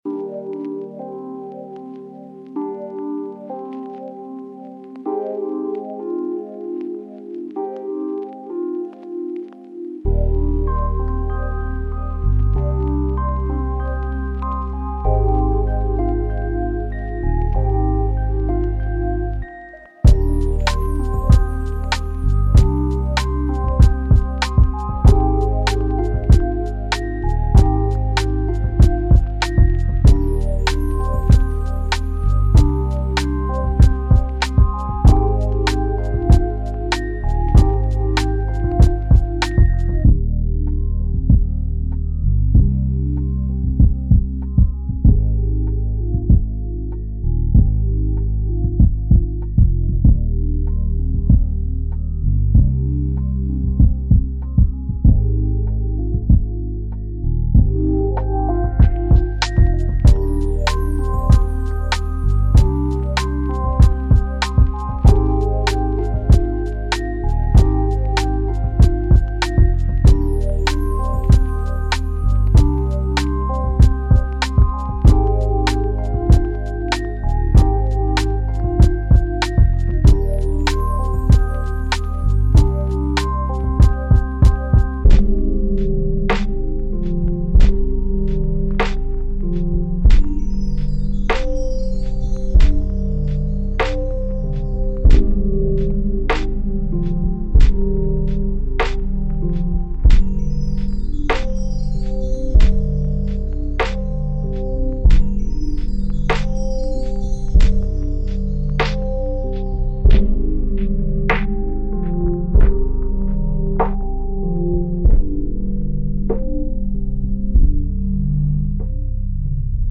Mozart Profond : Lecture Apaisée